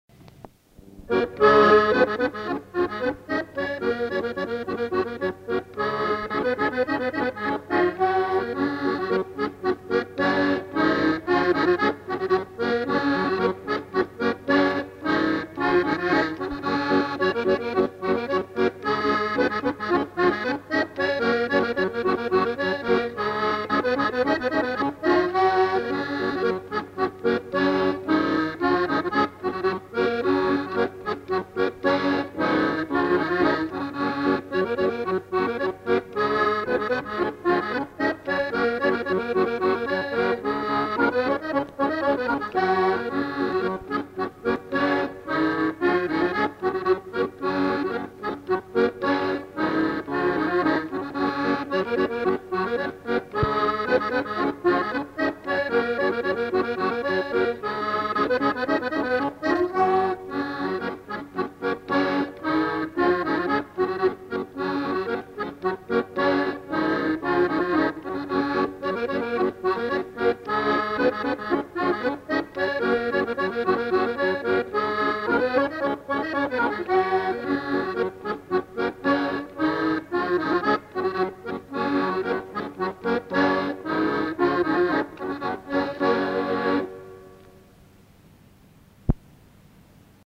Polka
Lieu : Monclar d'Agenais
Genre : morceau instrumental
Instrument de musique : accordéon diatonique
Danse : polka